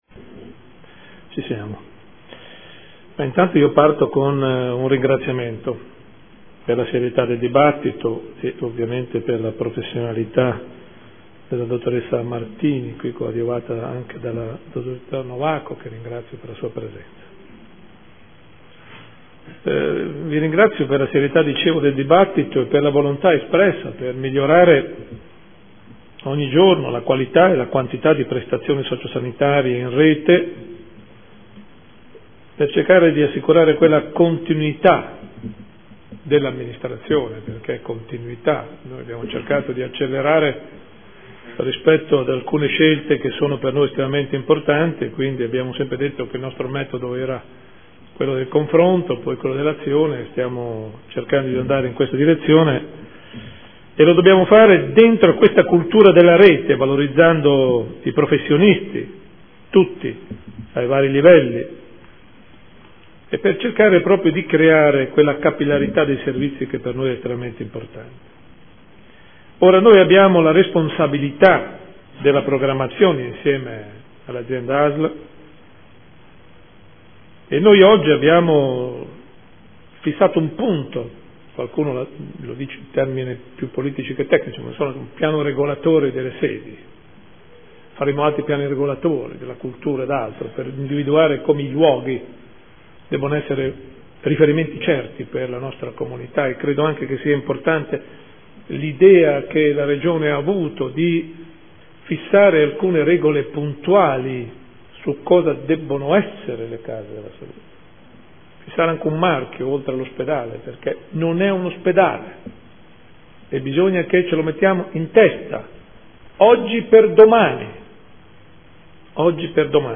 Seduta del 3/11/2014. Dibattito su ordini del giorno.